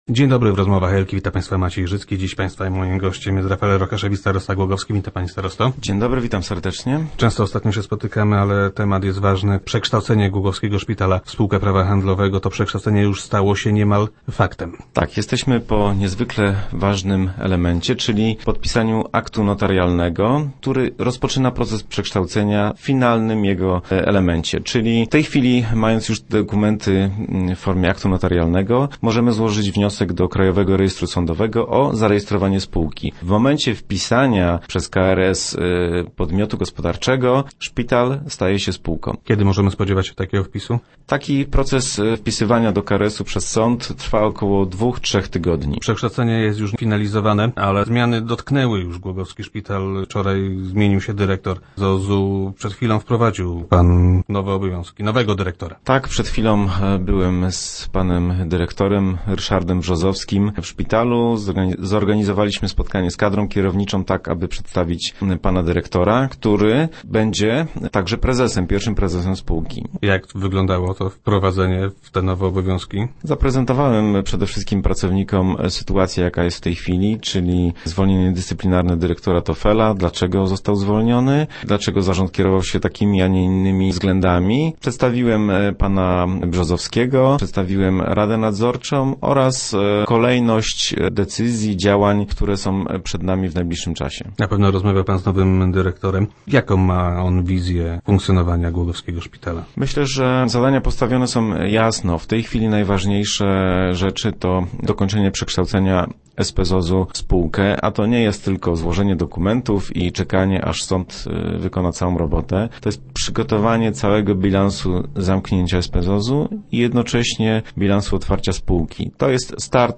Czy pacjenci mają powody do obaw? Gościem Rozmów Elki był starosta Rafael Rokaszewicz.